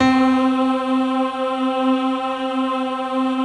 SI1 PIANO06L.wav